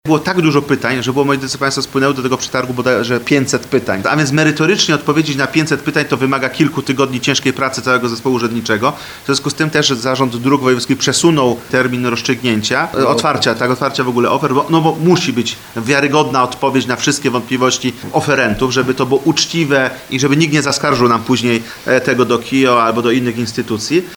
Tłumaczył poseł PiS Patryk Wicher, który również był obecny na konferencji prasowej.